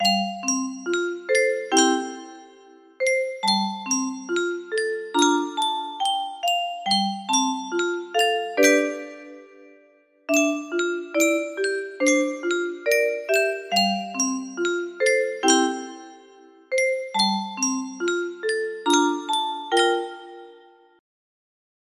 my mine music box melody